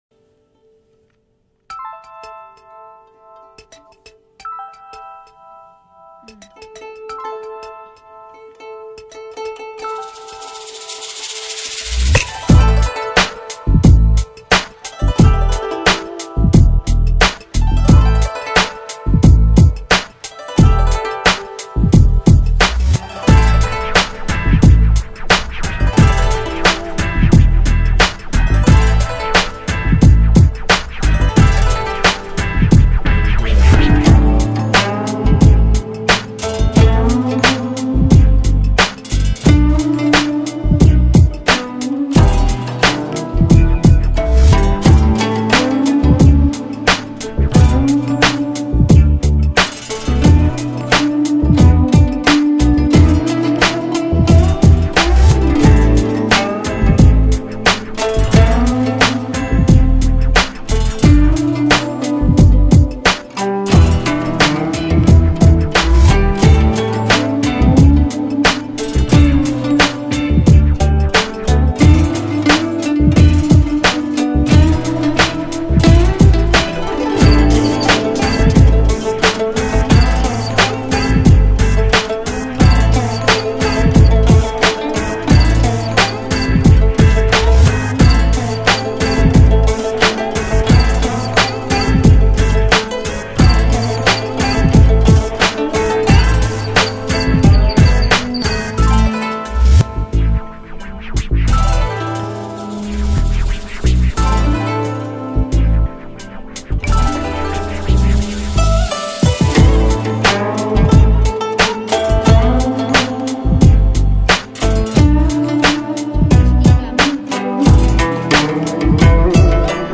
[3/2/2009]『一首歌就能把你征服』中国风系列之⒌【古筝慢摇，心跳起程】
忽忽~~ 莪还是对中国风情有独忠，这首古筝慢摇一听就让我想到了世外桃源的画面， 好的音乐不需要语言来诠释， 请大家细细品味吧，呵呵~